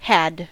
Ääntäminen
Vaihtoehtoiset kirjoitusmuodot (rikkinäinen englanti) 'ad hade hode Ääntäminen : IPA : /hæd/ US : IPA : [hæd] Haettu sana löytyi näillä lähdekielillä: englanti Käännöksiä ei löytynyt valitulle kohdekielelle.